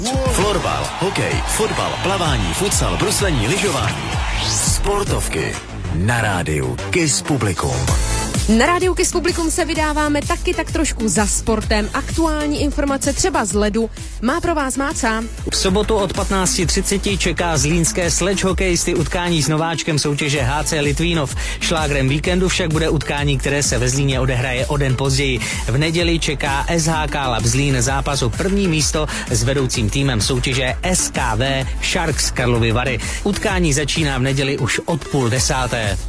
Upoutávka na 11. a 12. kolo ČSHL.